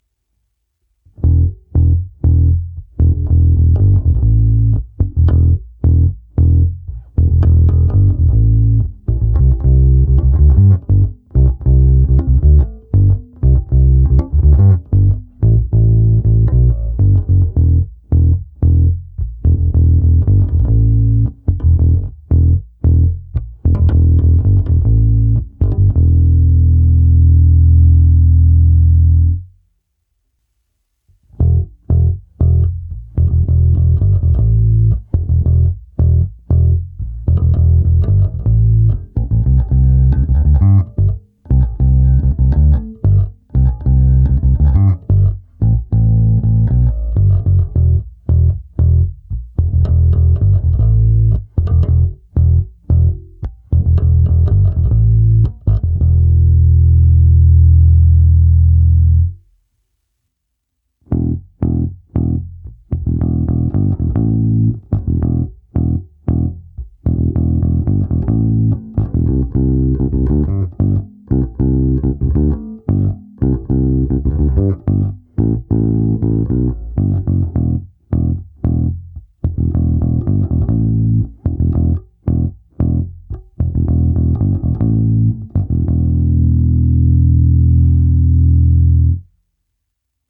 Ty jsem udělal ve stejném pořadí jako výše, ale použil jsem u nich rovnou už i simulaci aparátu. U první ukázky je hra prsty, pak trsátkem a na jejím konci ještě přidáno hraní stylem palm muting prsty a pak trsátkem, ale už jen na oba snímače.